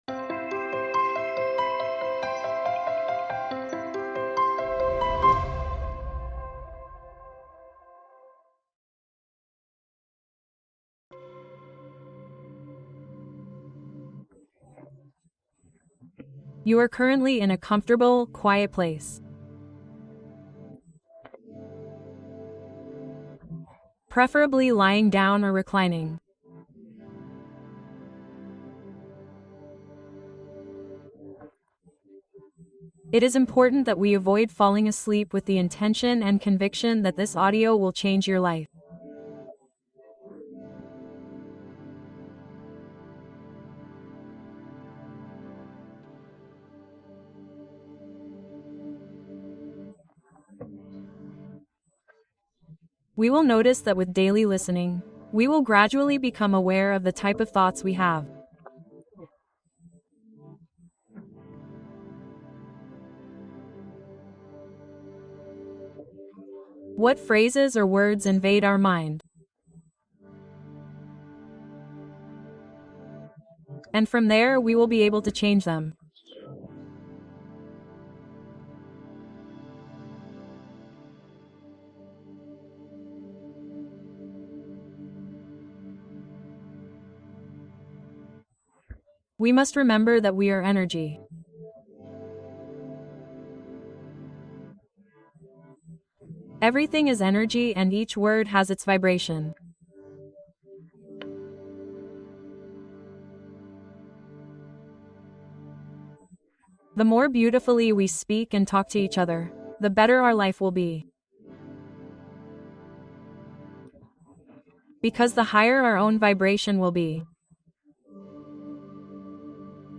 Meditación guiada para transformar tu mente hacia el pensamiento positivo